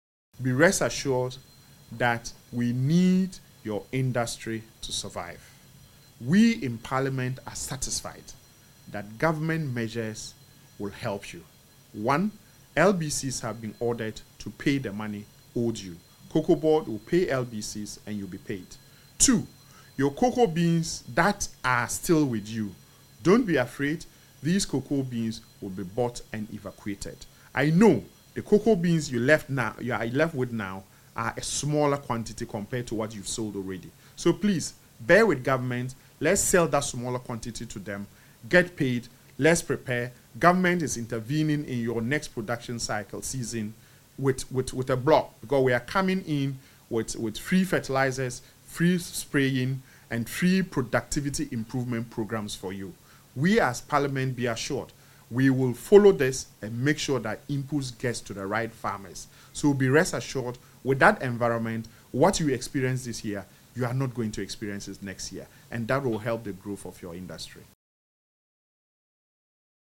Addressing journalists, Chairman of the Food, Agriculture and Cocoa Affairs Committee, Godfred Seidu Jasaw, said the price adjustment was partly influenced by prevailing rates in neighbouring cocoa-producing countries to prevent reverse smuggling into Ghana.